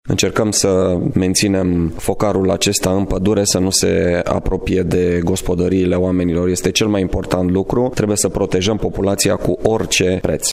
Prefectul judeţului Braşov, Marian Rasaliu:
stiri-20-oct-prefect.mp3